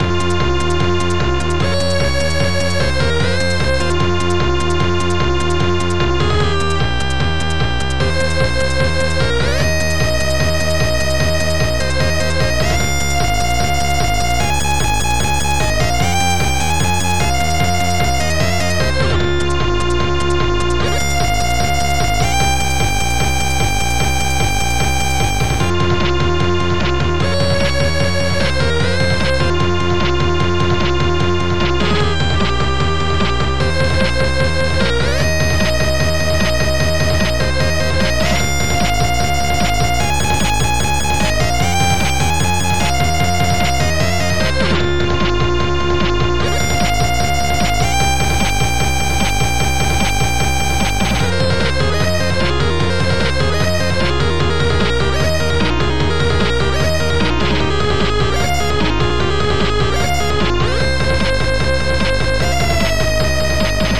Protracker M.K.